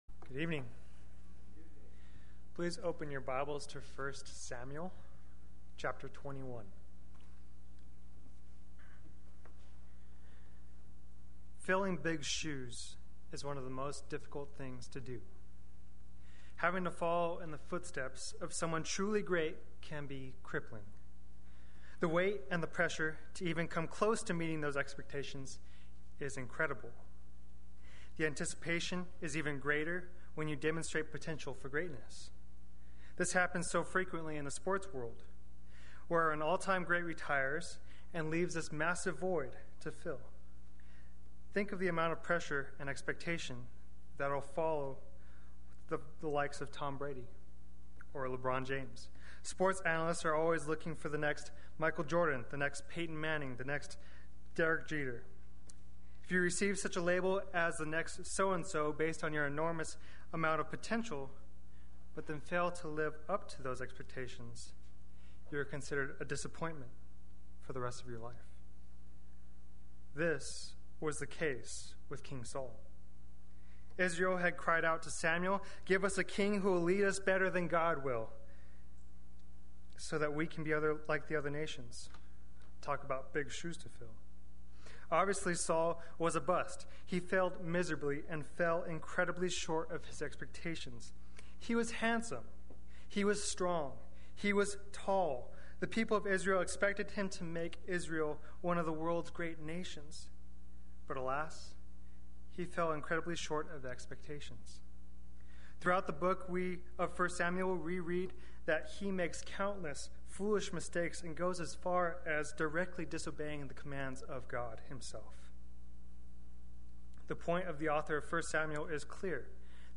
Play Sermon Get HCF Teaching Automatically.
The Temptation of the King Wednesday Worship